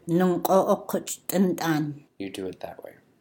That way /eqech’/